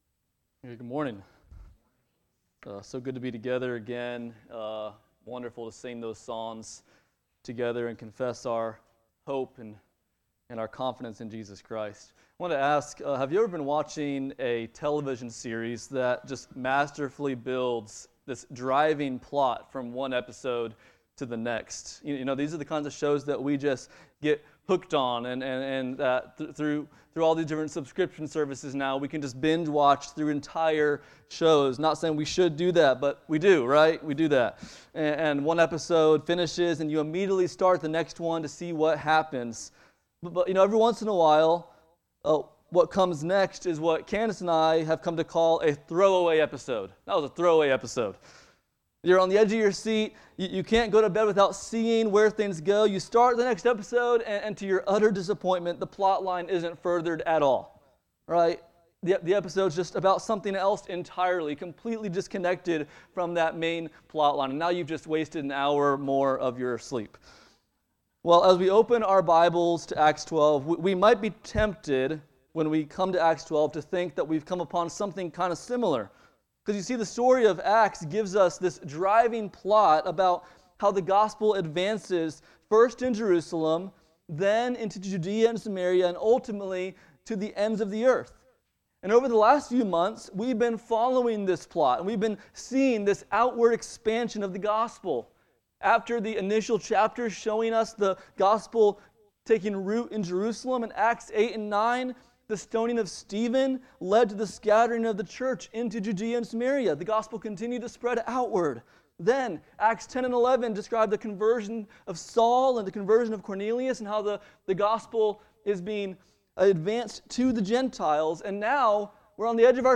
Passage: Acts 12:1-24 Service Type: Sunday Morning